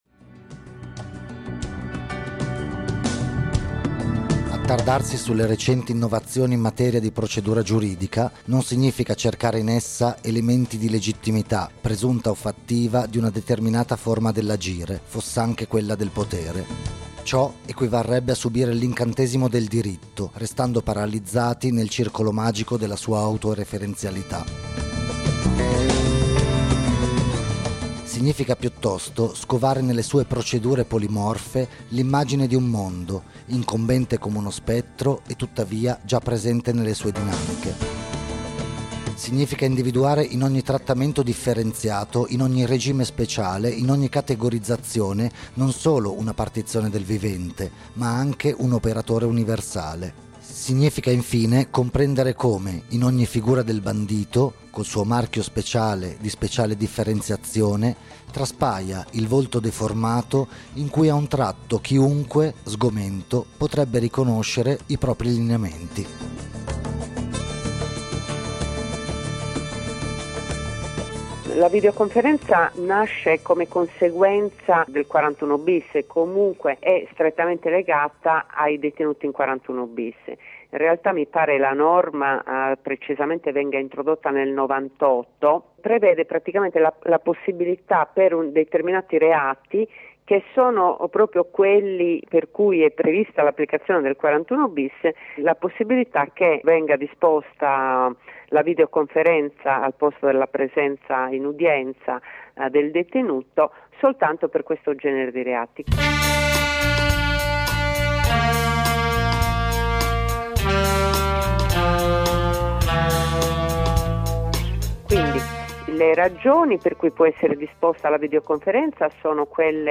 Su tutto ciò abbiamo chiesto alcune delucidazioni a un avvocato